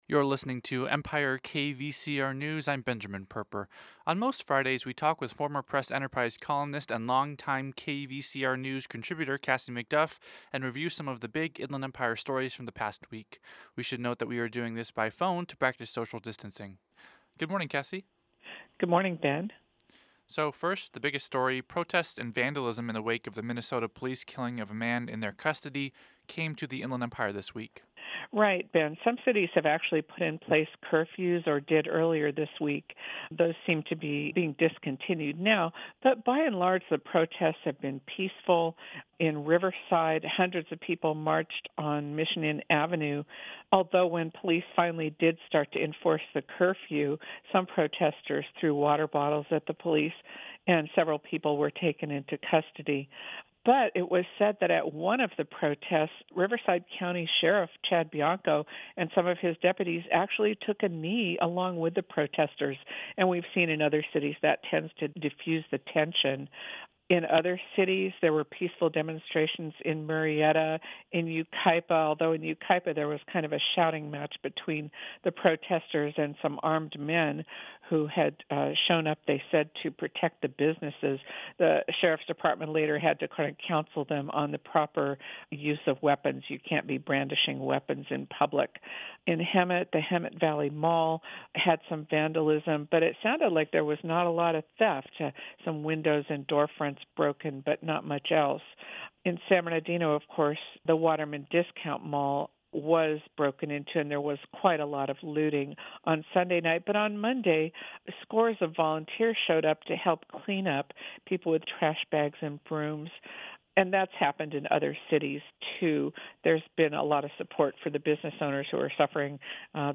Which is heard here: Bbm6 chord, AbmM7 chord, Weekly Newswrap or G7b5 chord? Weekly Newswrap